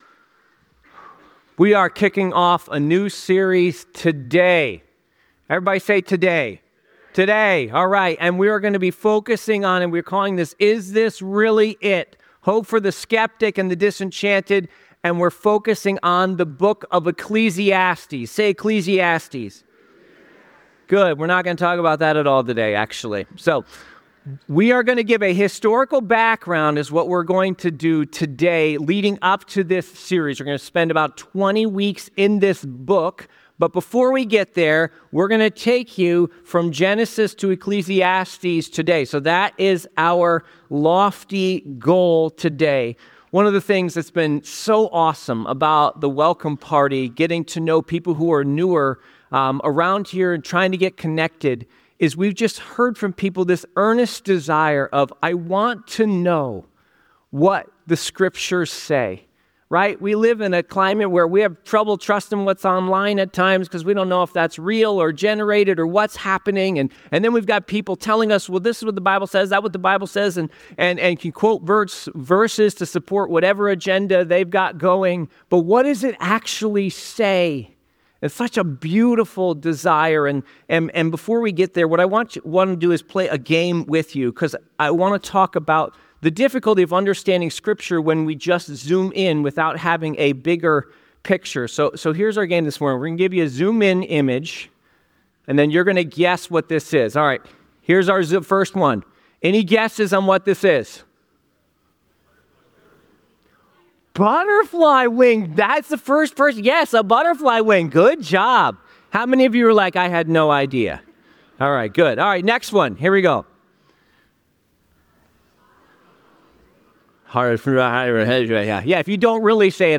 This sermon kicks off a new series titled 'Is This Really It?' by focusing on the book of Ecclesiastes. This initial sermon provides a historical background leading up to Ecclesiastes, covering 1,140 years of biblical history starting from Genesis, tracing the journey from Abraham to Solomon.